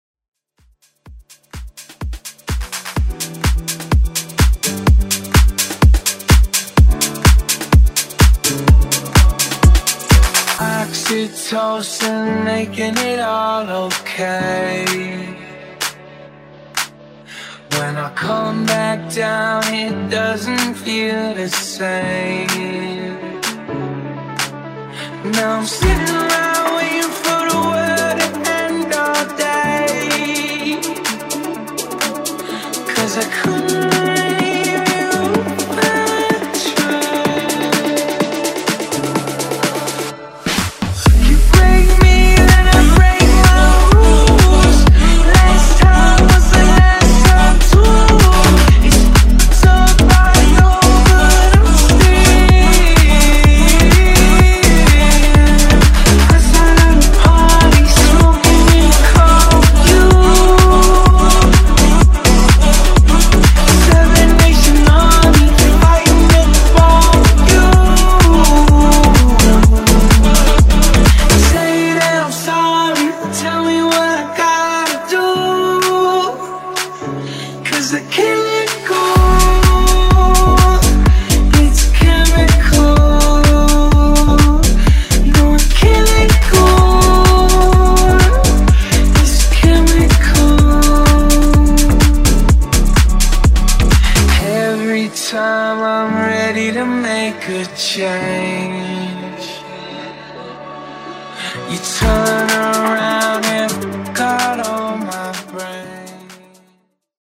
Genres: FUTURE HOUSE , MASHUPS , TOP40
Clean BPM: 126 Time